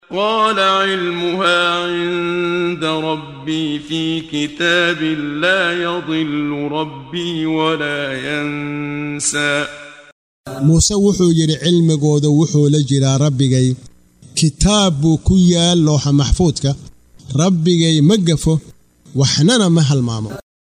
Waa Akhrin Codeed Af Soomaali ah ee Macaanida Suuradda Ta Ha oo u kala Qaybsan Aayado ahaan ayna la Socoto Akhrinta Qaariga Sheekh Muxammad Siddiiq Al-Manshaawi.